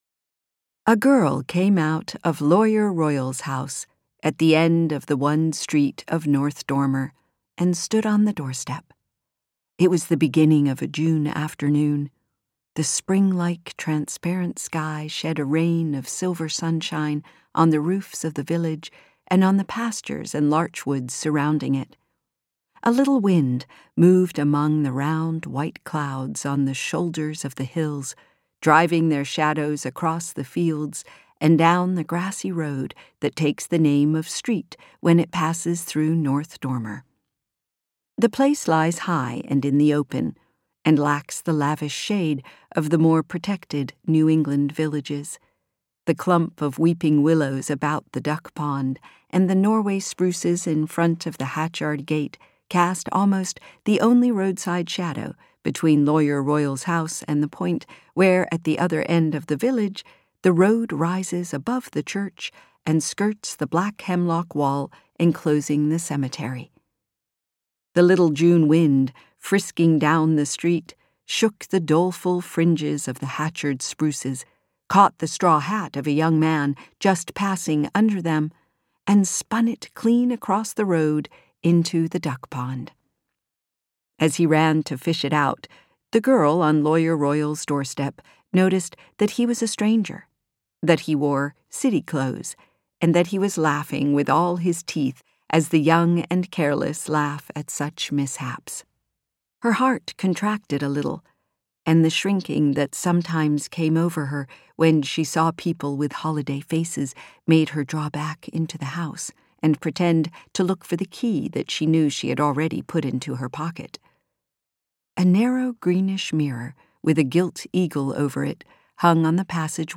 Summer audiokniha
Audiobook Summer, written by Edith Wharton.
Ukázka z knihy